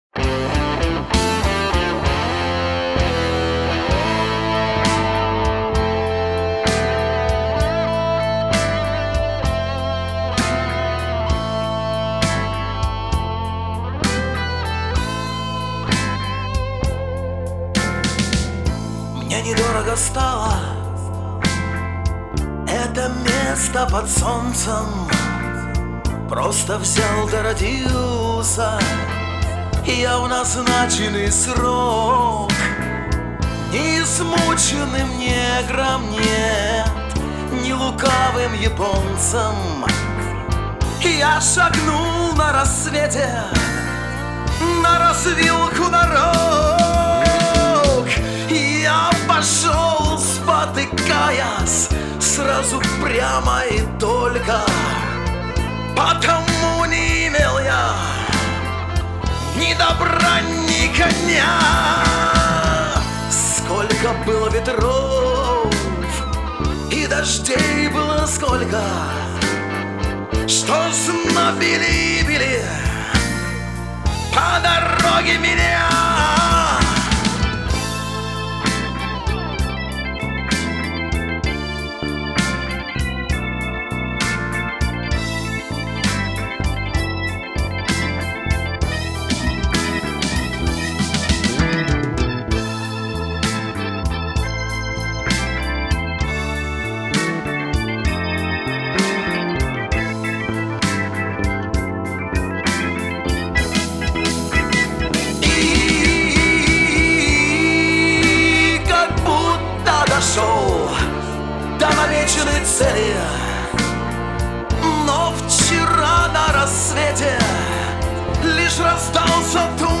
Рок-группа
( Записан в апреле 2009 - феврале 2010 года своими силами )